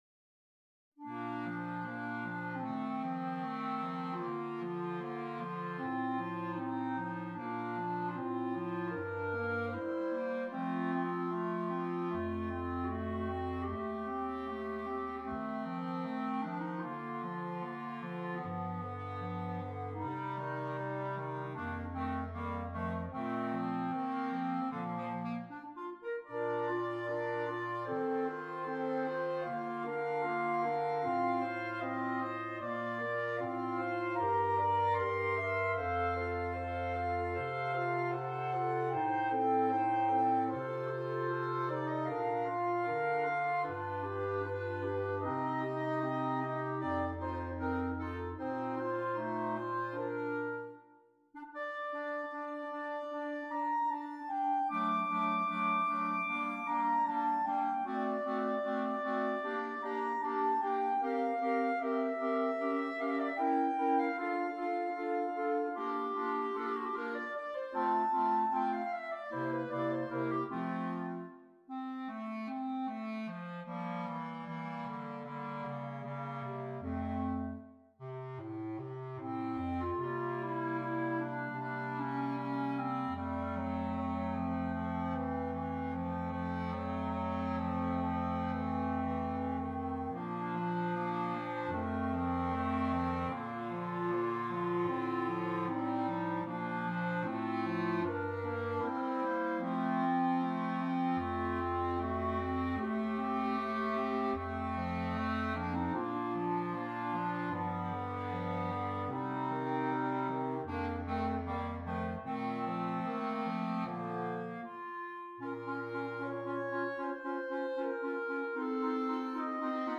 3 Clarinets, Bass Clarinet
A slow and expressive second movement